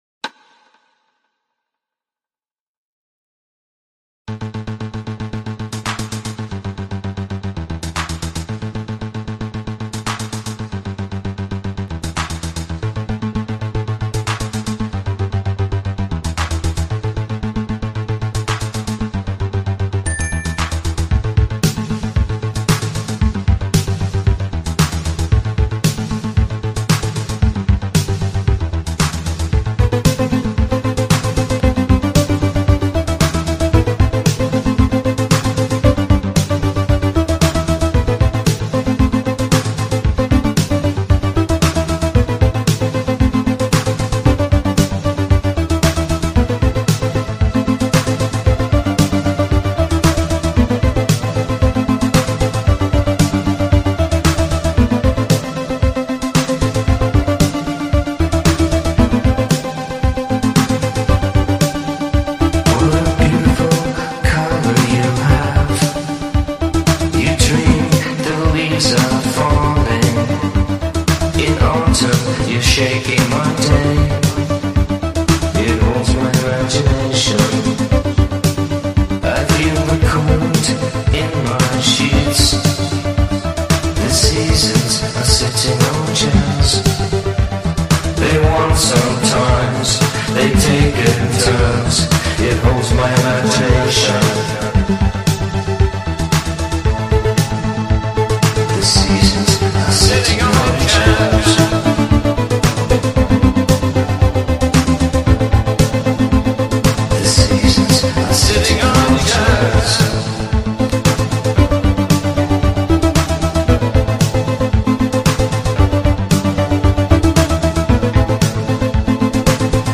DarkPop